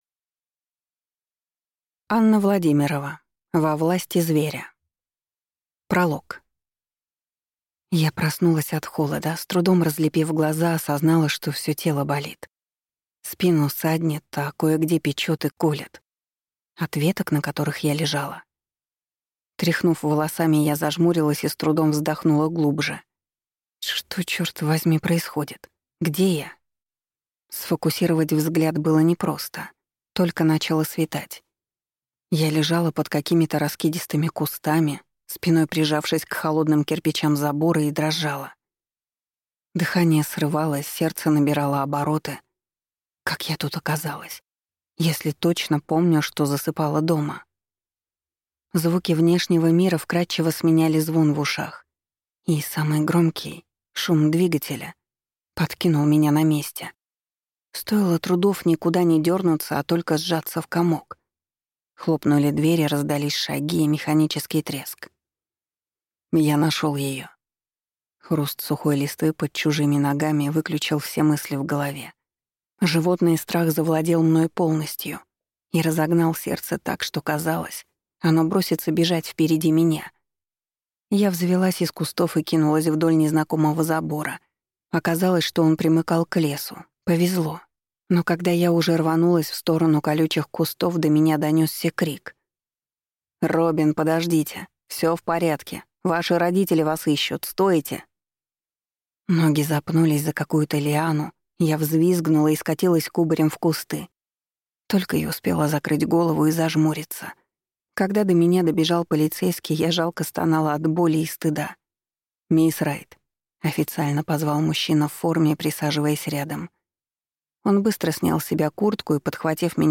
Аудиокнига Во власти зверя | Библиотека аудиокниг